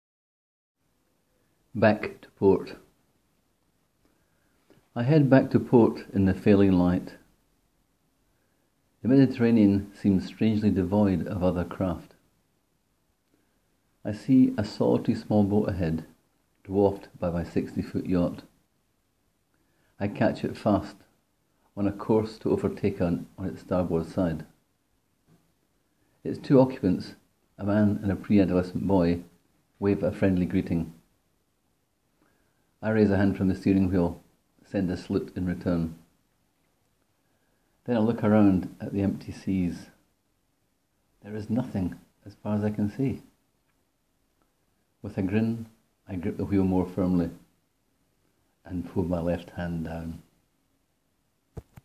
Click here to hear me read the story: